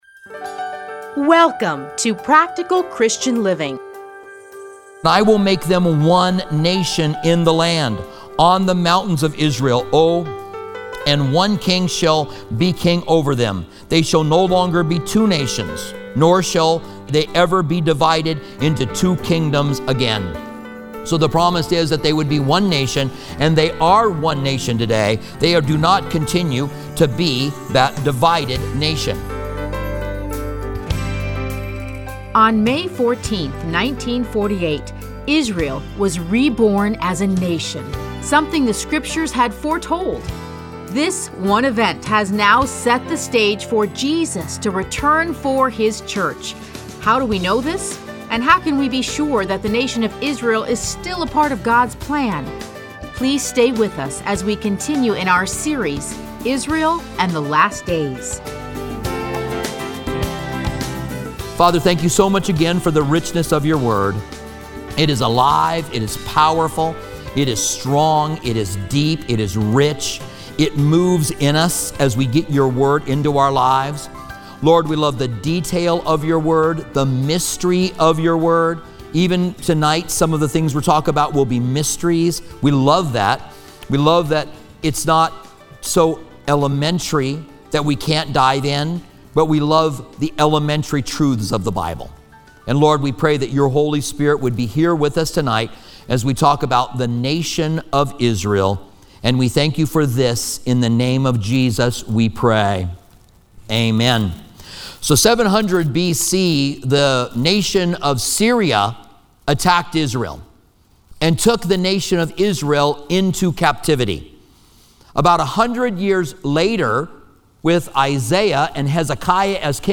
Listen to a teaching from Ezekiel 37-39.